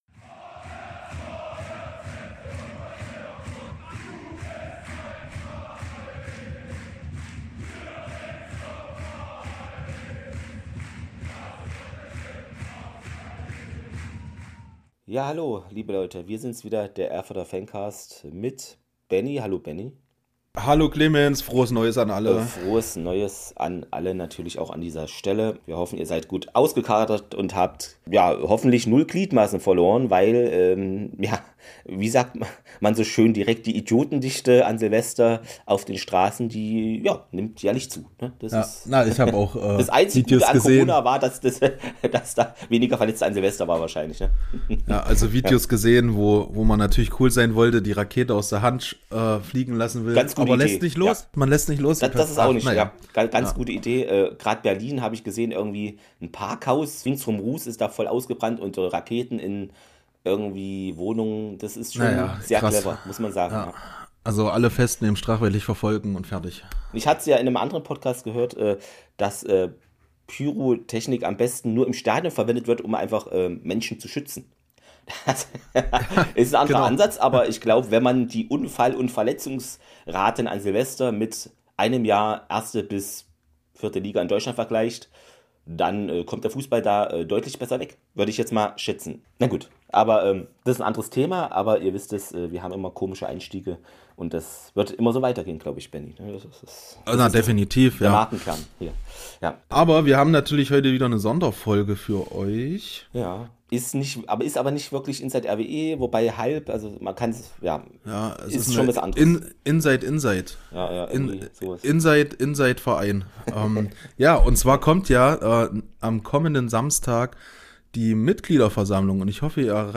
Am Fr. fand die vom Fanrat organisierte digitale Gesprächsrunde statt. Es stellten sich viele Personen vor, welche für den Ehrenrat oder Aufsichtsrat kandidieren. Dies ist eine verkürzte Version inkl. unserer Einordnung und einem Ausblick auf die anstehende Mitgliederversammlung.